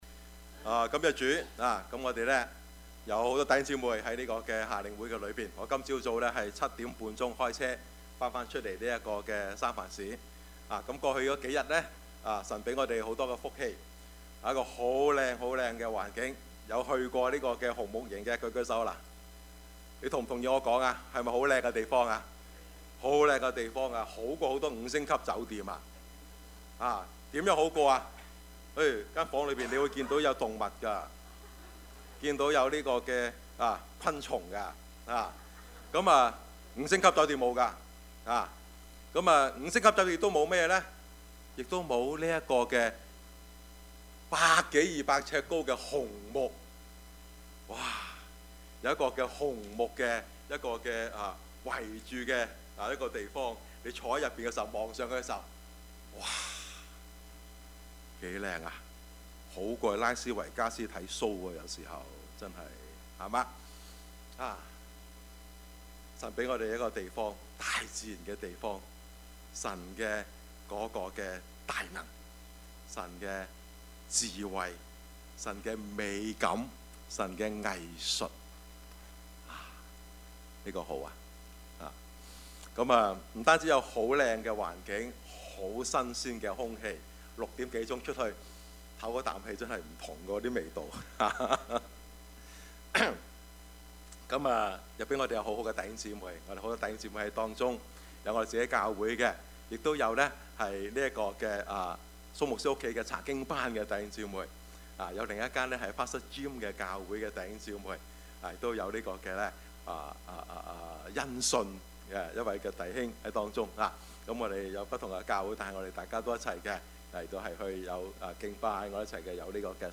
Topics: 主日證道 « 痛而不苦 生之無奈 »